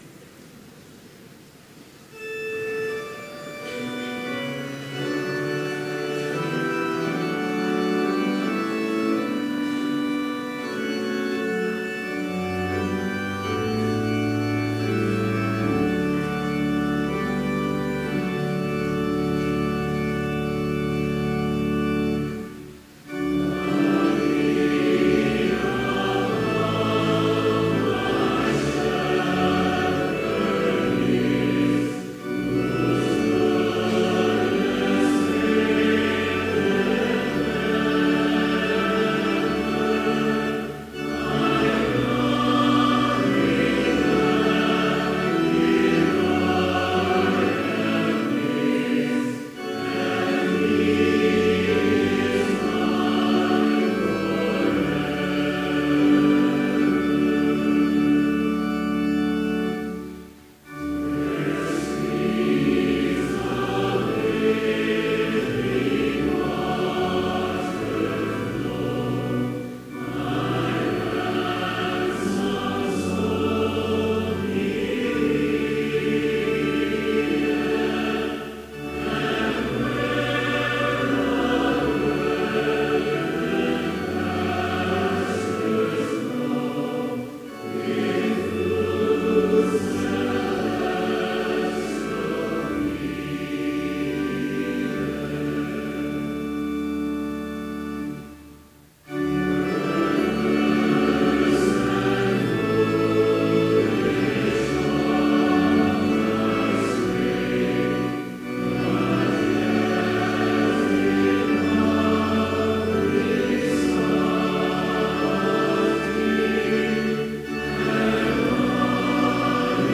Complete service audio for Chapel - September 28, 2016